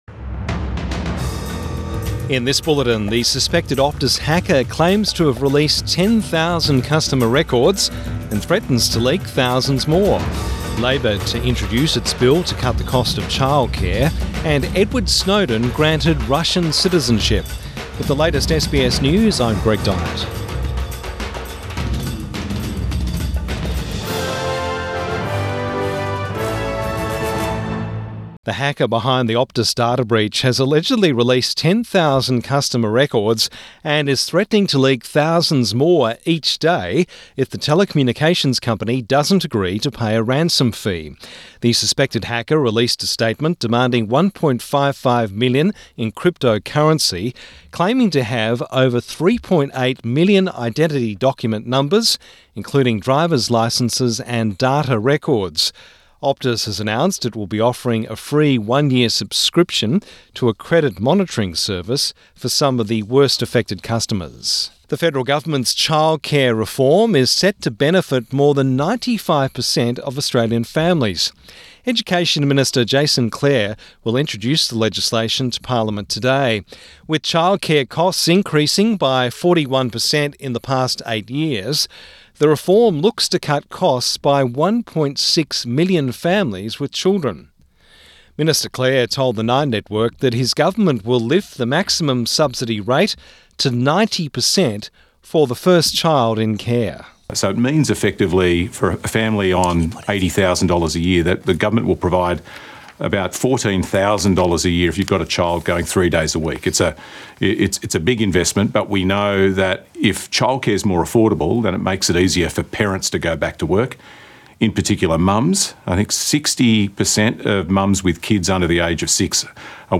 Midday bulletin 27 September 2022